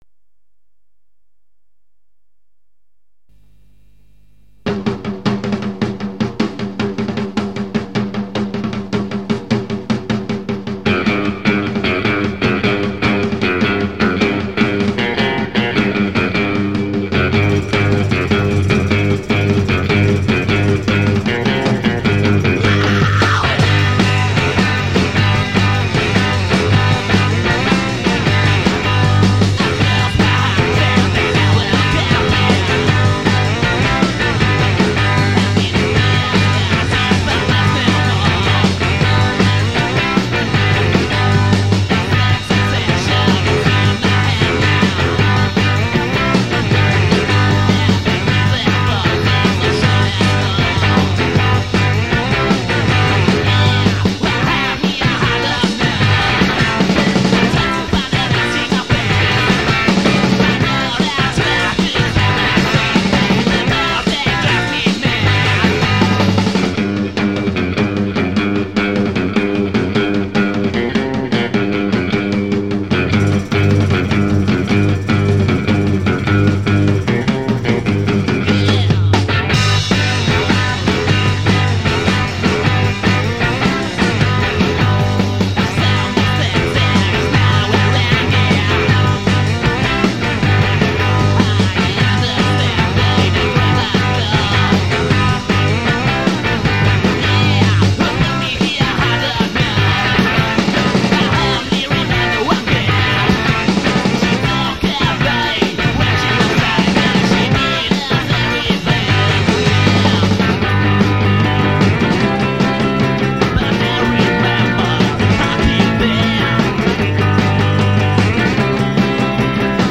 Basso
Batteria & cori
Voce e tambourine
Chitarra fuzz